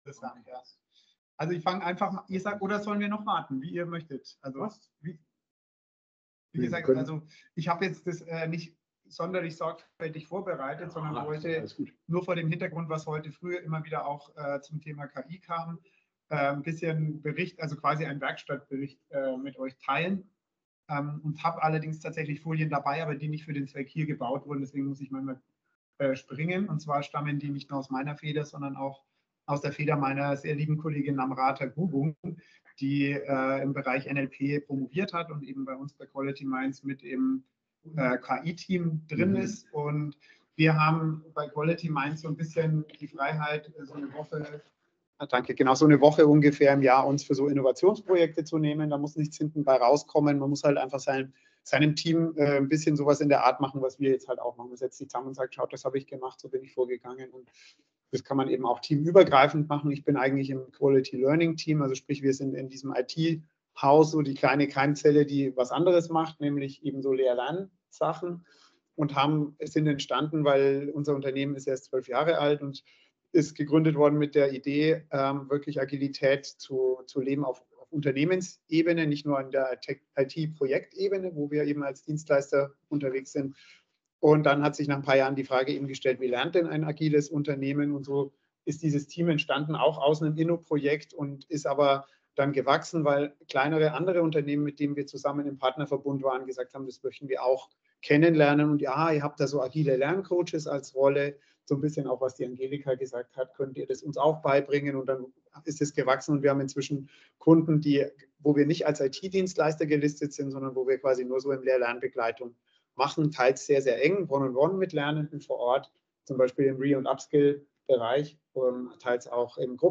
Beschreibung vor 2 Monaten Dieser Vortrag präsentiert einen praxisorientierten Werkstattbericht über den Einsatz von Large Language Models (LLMs) in Unternehmen, speziell zur Bewältigung des demografischen Wandels und Wissenstransfers. Das Hauptaugenmerk liegt auf der Entwicklung einer datenschutzkonformen Lösung mittels Embedding Models, die es ermöglicht, interne Unternehmensdaten zu nutzen, ohne diese in externe KI-Systeme zu übertragen oder das zugrundeliegende Modell zu trainieren.